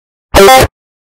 discord earrape ping
discord-earrape-ping.mp3